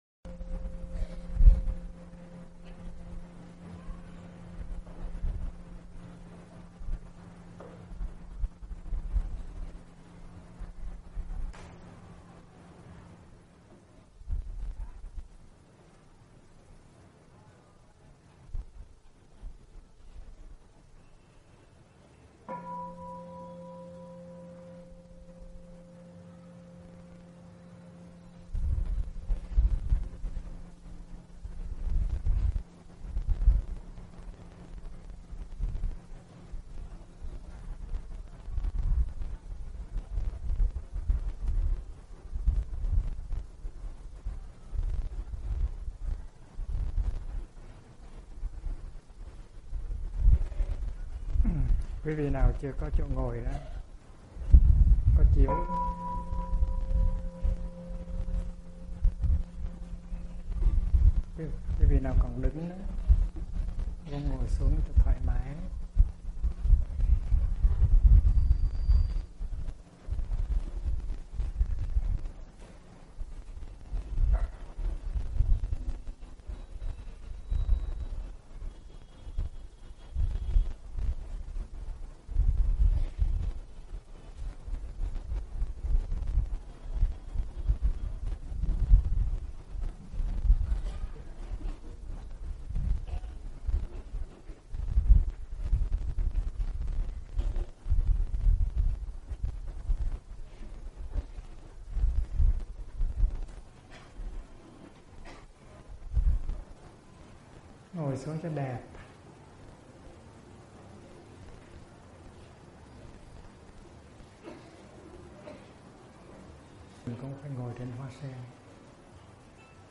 Mời quý phật tử nghe mp3 Pháp thoại tưởng niệm các tác viên phụng sự xã hội do HT. Thích Nhất Hạnh giảng